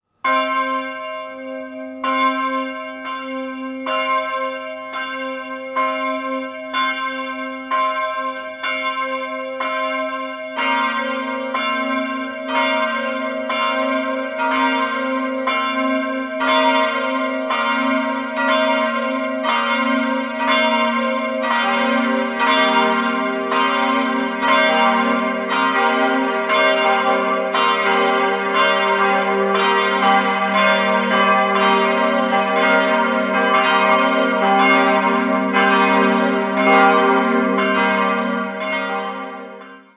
Innenansicht wird noch ergänzt. 3-stimmiges TeDeum-Geläute: g'-b'-c'' Die Glocken wurden im Jahr 1971 in der Glockengießerei Heidelberg gegossen und wiegen 949, 550 und 415 kg.